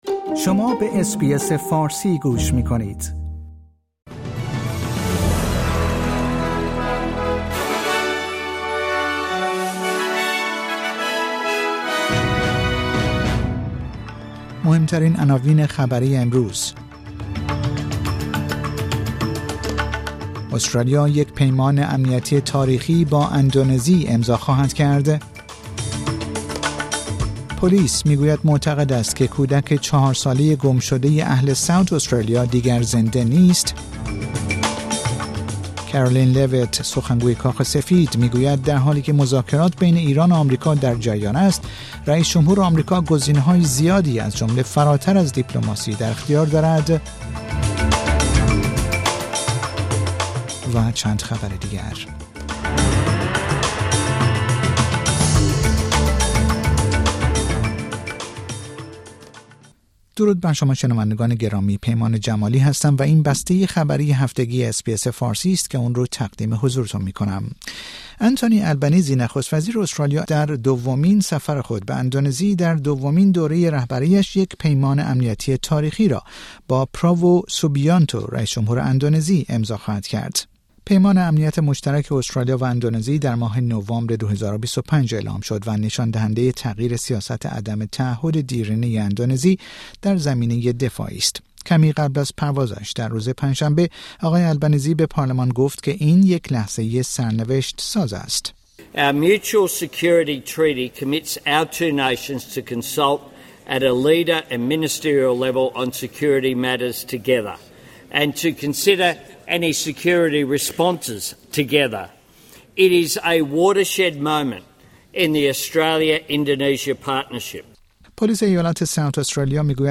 در این پادکست خبری مهمترین اخبار هفته منتهی به جمعه ششم فوریه ۲۰۲۶ ارائه شده است.